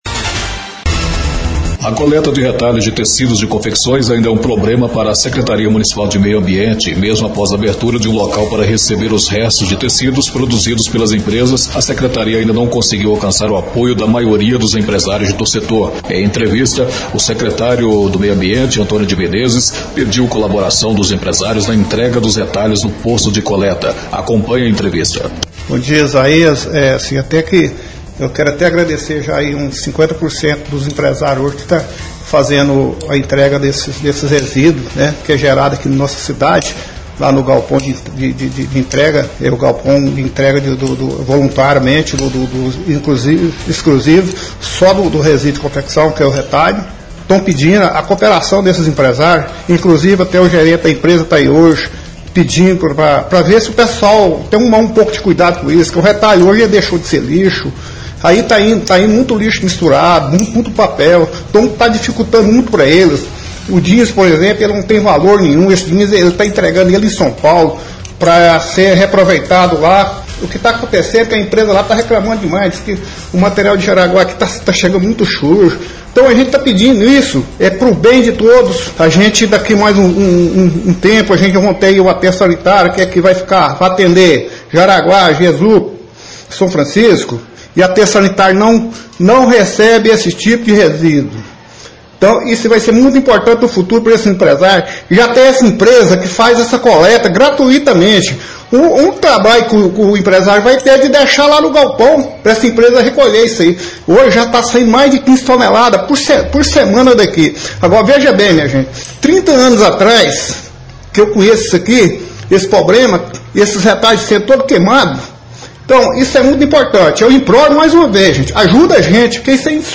Em entrevista, o secretário da SMMA (Secretaria Municipal de Meio Ambiente), Antônio de Menezes, pediu colaboração dos empresários na entrega dos retalhos no posto de coleta.